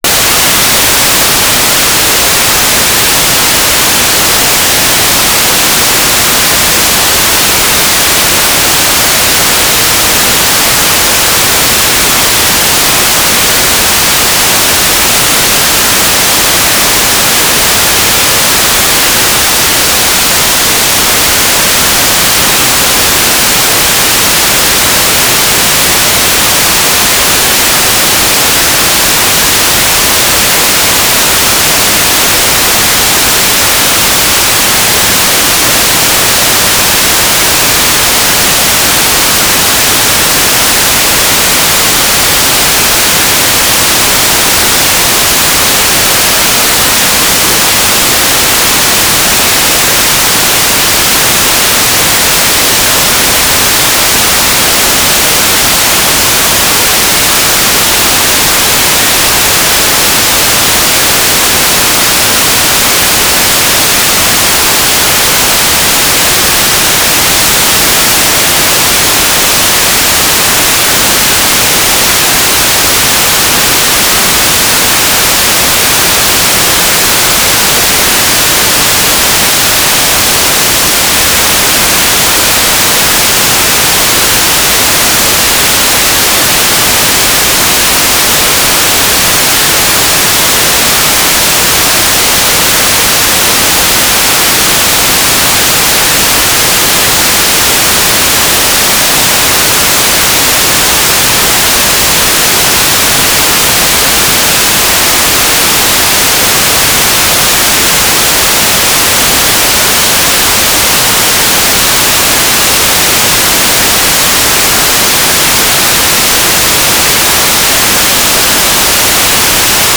"transmitter_description": "Mode U - GMSK2k4 - USP",